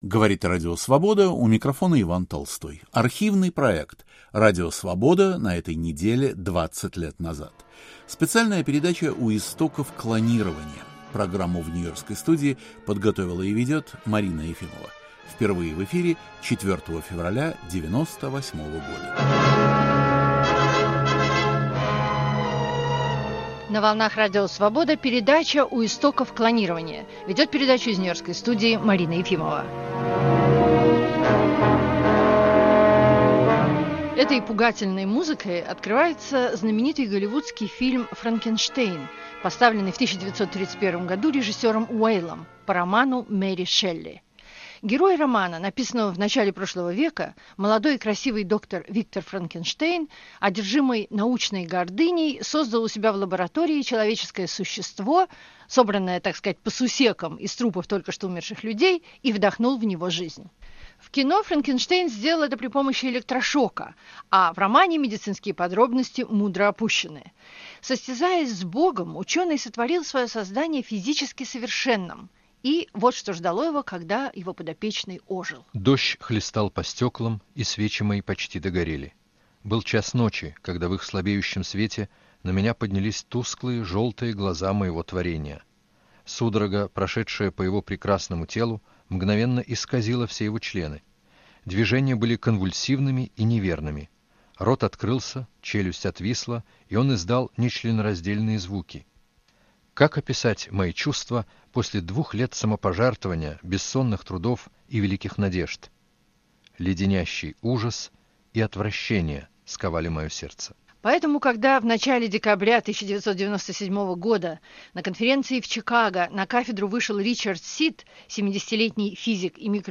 Клонирование людей - "за" и "против" (1998). Обсуждают ученые, юрист и специалист по биоэтике, писатель, философ.